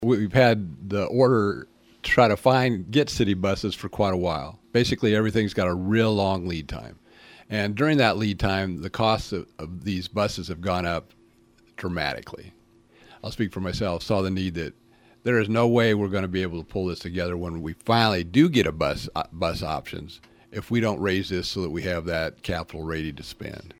That is City Councilor Greg Nichols who says the increase is all based on the public transit budget.